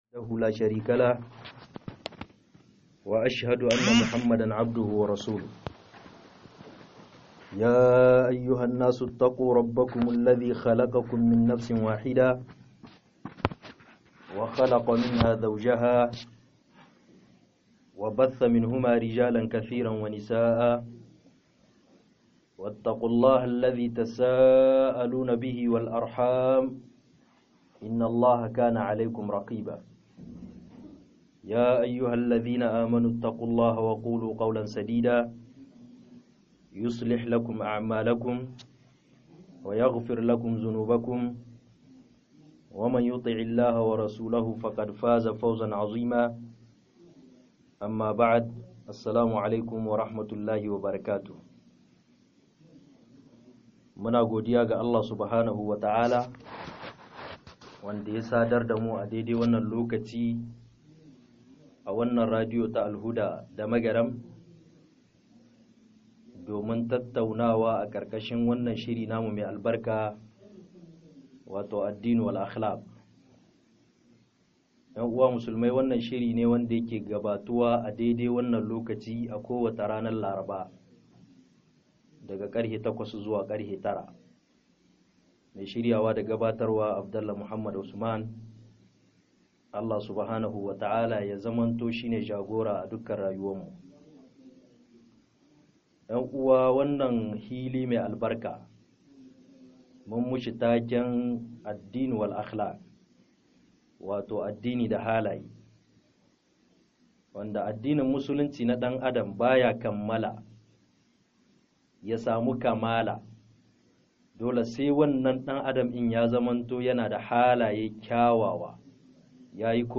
07 ADINI-DA-HALAYE - MUHADARA